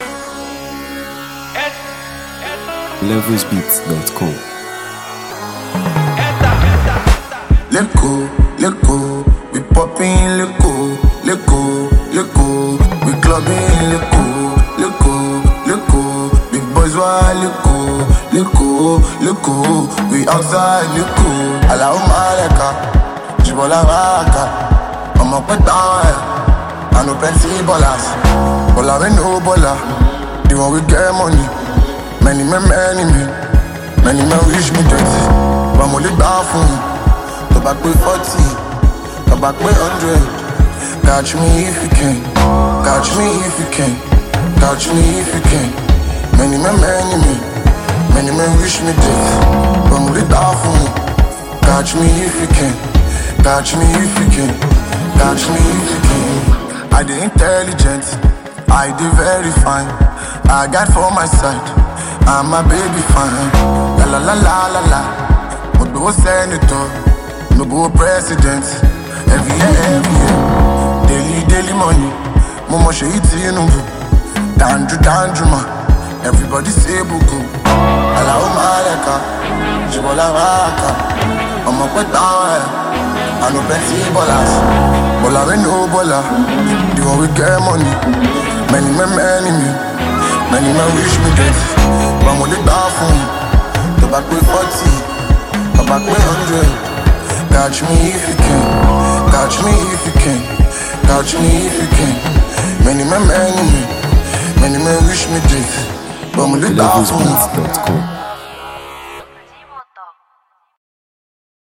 Nigeria Music 2025 1:51
rhythmic depth
From its captivating production to its infectious hook
It’s a vibrant, soul-stirring tune